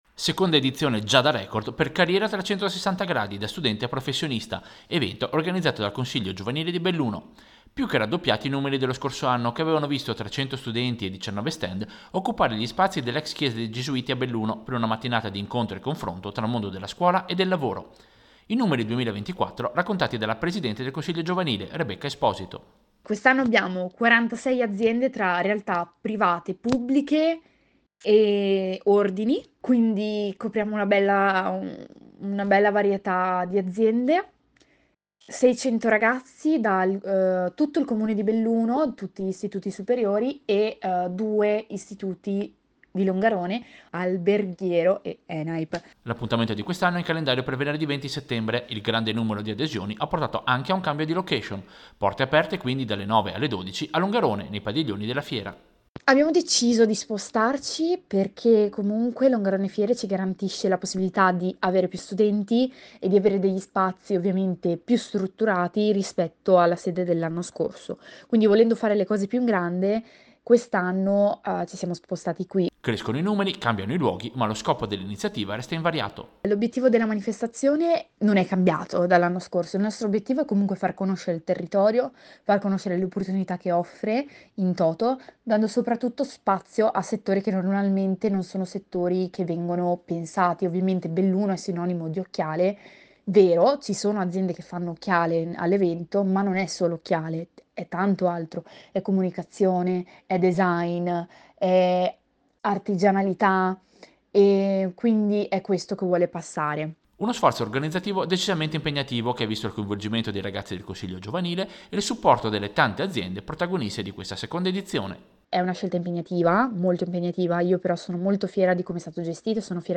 Servizio-Presentazione-studenti-aziende-Longarone-2024-1.mp3